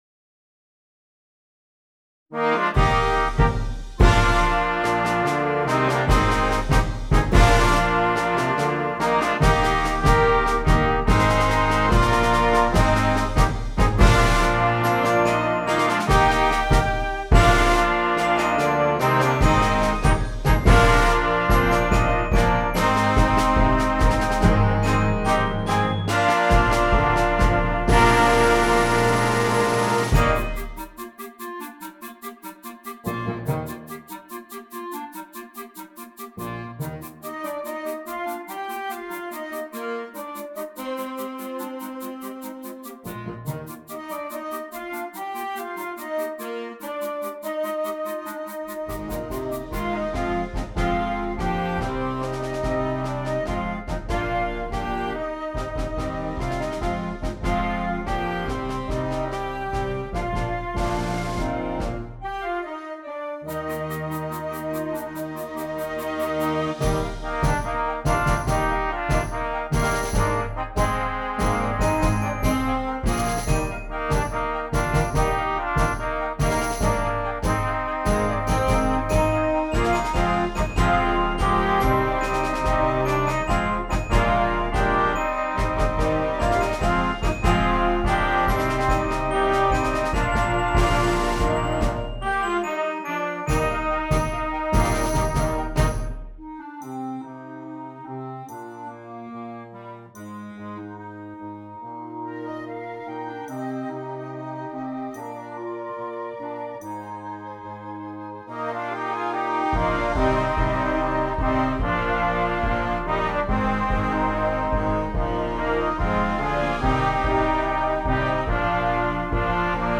Concert Band
a fun, upbeat piece